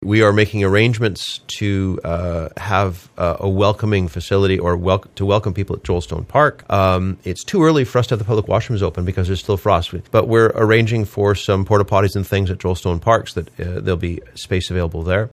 The total Solar Eclipse and the Town of Gananoque is welcoming everyone to the region with a viewing party at Joel Stone Park today. Here’s Gananoque Town Mayor John Beddows.